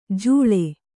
♪ jūḷe